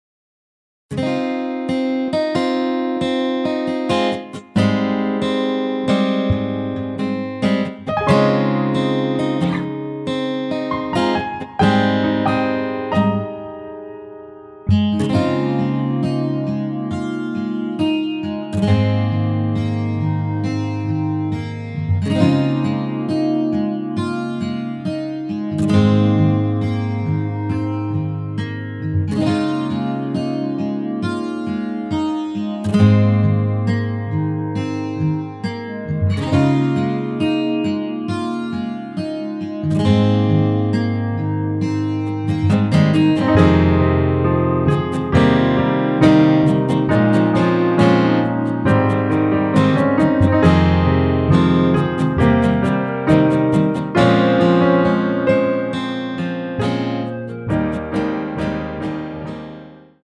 원키에서(-3)내린 멜로디 포함된 MR 입니다.(미리듣기 참조)
Ab
앞부분30초, 뒷부분30초씩 편집해서 올려 드리고 있습니다.